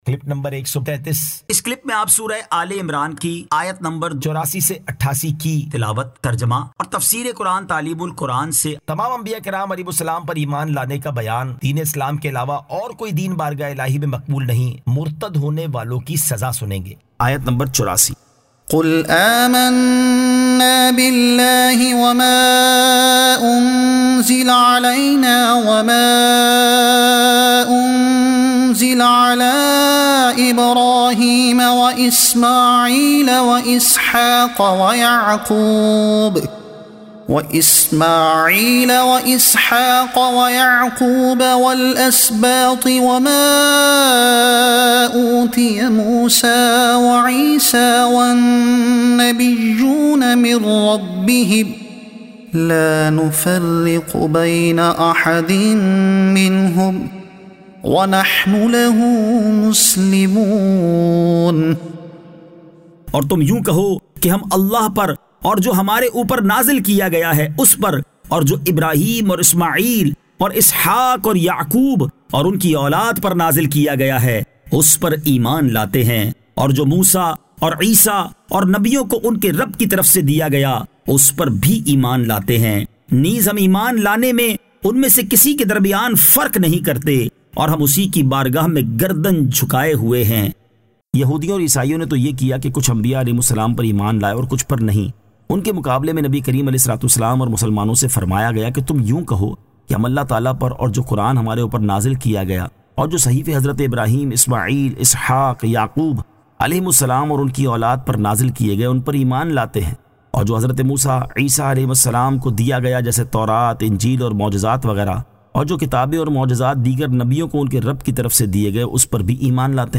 سُوَّرۃُ اٰل ِعِمْرَانْ آیت 84 تا 88 تلاوت ، ترجمہ ، تفسیرِ تعلیم القرآن ۔